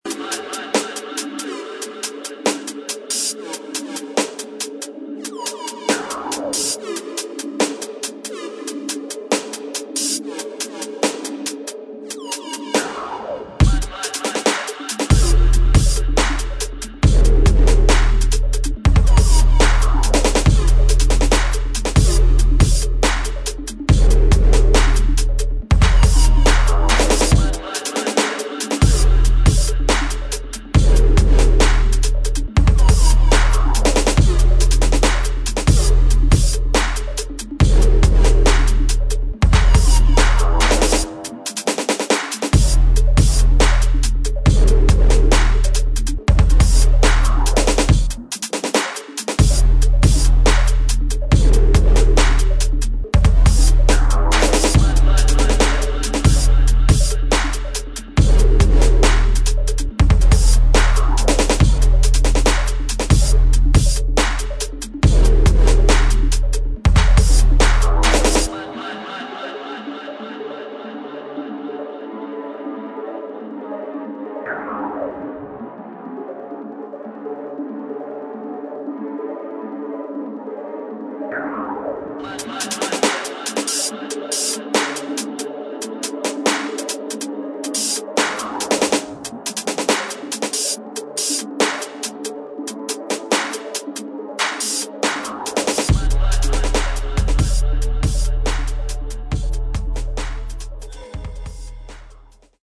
GRIME